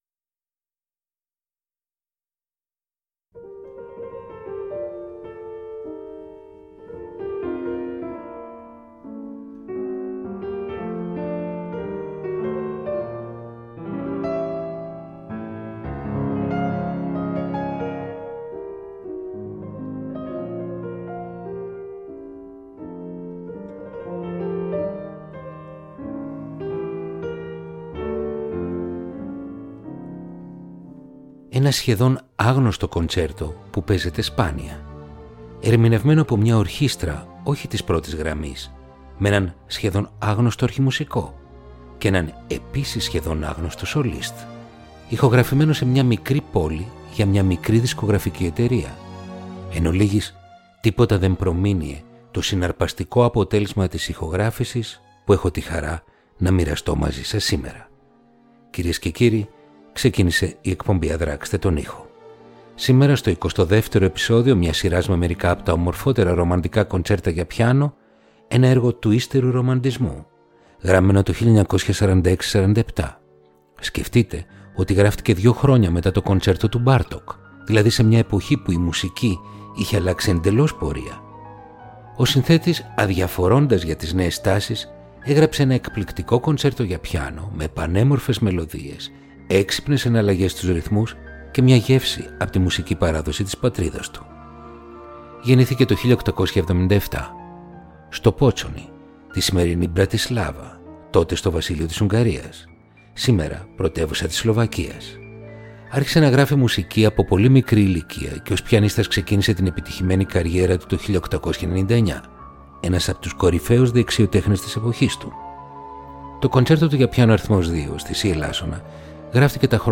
Ρομαντικά κοντσέρτα για πιάνο – Επεισόδιο 22ο